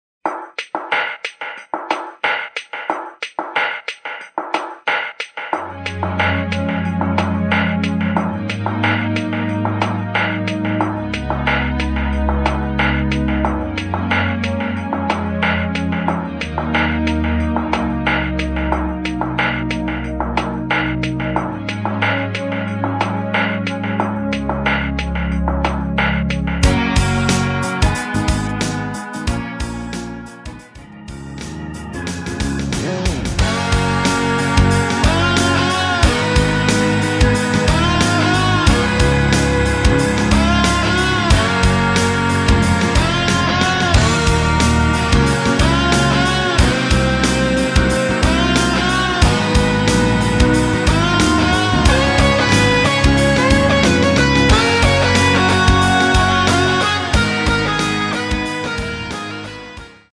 내린 MR 입니다.
원곡의 보컬 목소리를 MR에 약하게 넣어서 제작한 MR이며